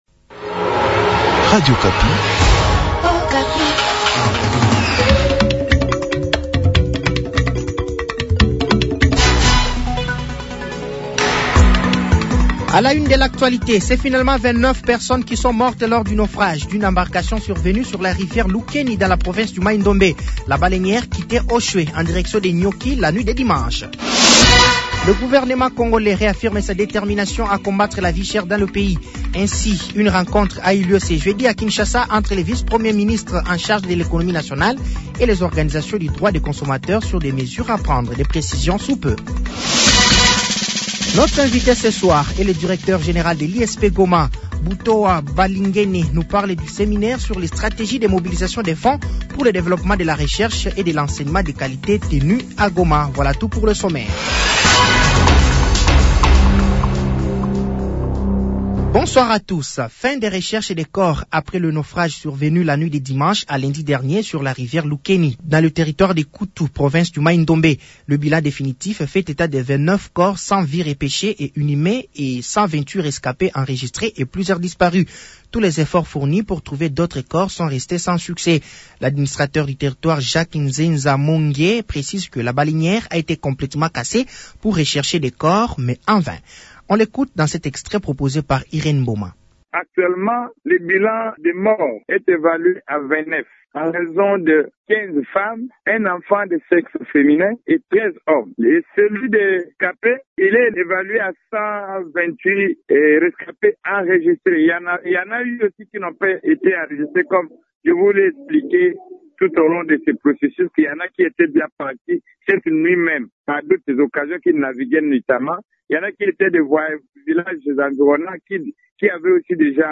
Journal français de 18h de ce vendredi 23 août 2024